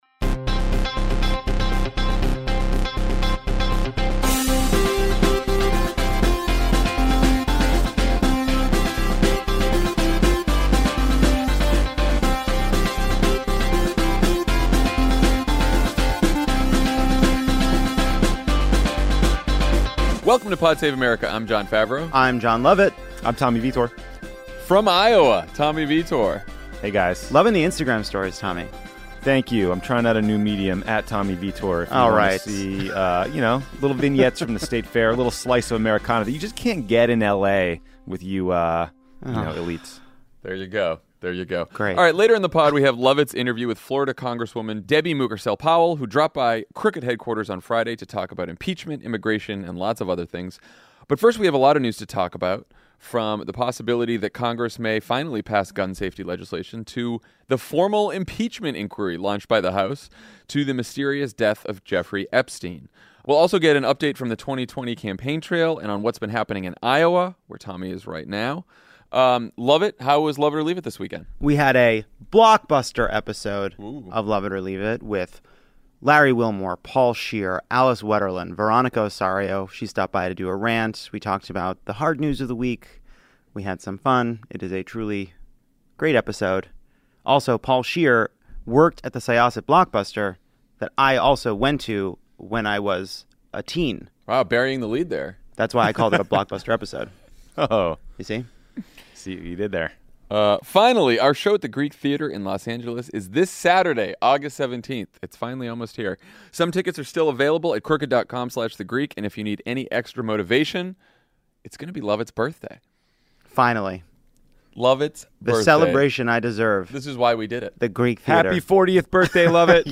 Trump says he’s open to background check legislation, House Democrats announce they’re in the middle of an impeachment inquiry, Jeffrey Epstein’s apparent suicide is investigated, and the media focuses on Joe Biden’s latest gaffes. Then Congresswoman Debbie Mucarsel-Powell talks to Jon L. about impeachment, immigration, and more.